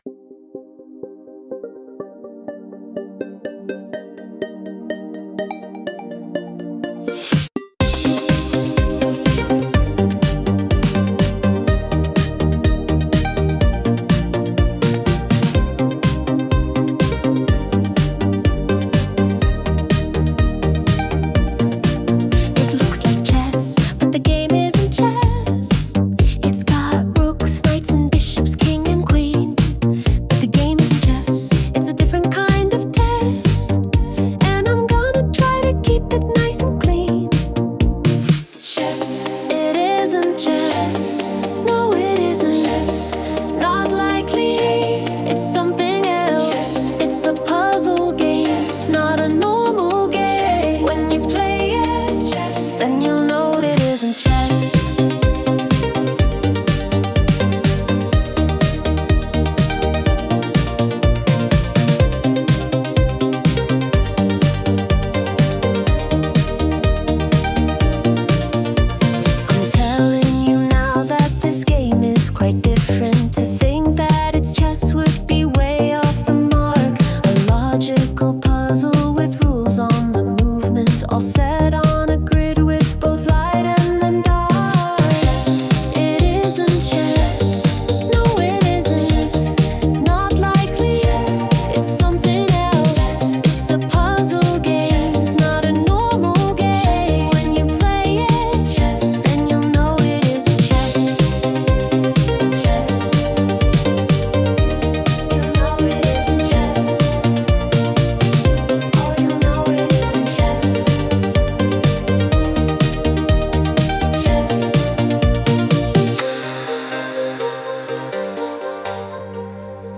Sung by Suno
Not_Chess_(Remix)_mp3.mp3